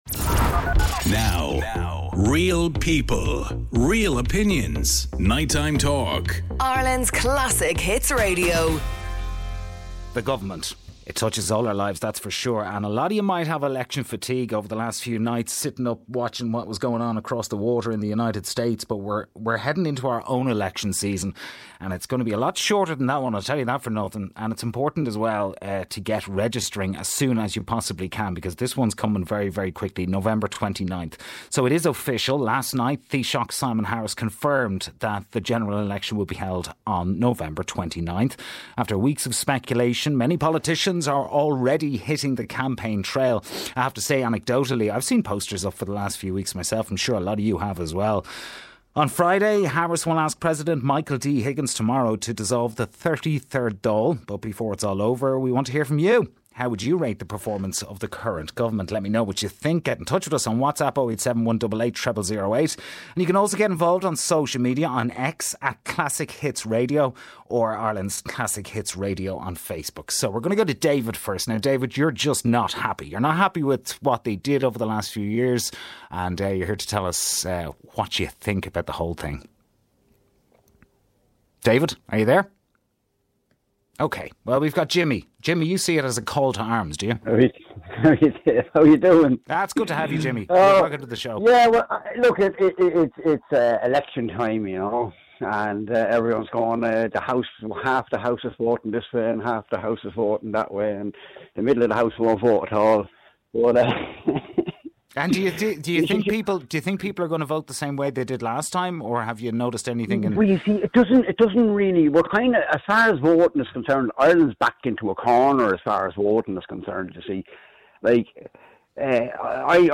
A radio talk show that cares about YOU